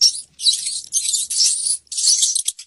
Tikus_Suara.ogg